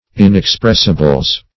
Search Result for " inexpressibles" : The Collaborative International Dictionary of English v.0.48: Inexpressibles \In`ex*press"i*bles\, n. pl. Breeches; trousers;; underwear generally, especially women's; -- now usually referred to as unmentionables .